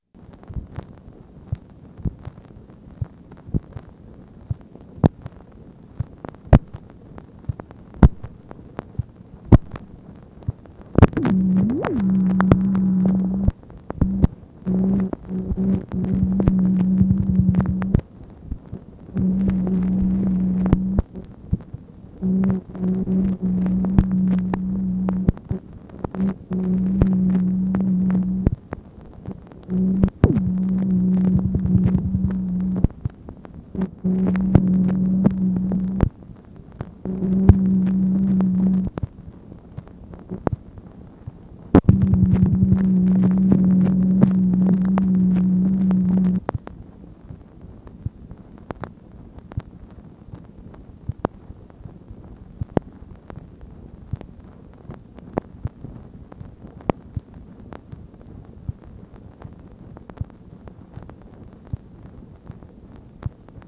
MACHINE NOISE, May 20, 1965
Secret White House Tapes | Lyndon B. Johnson Presidency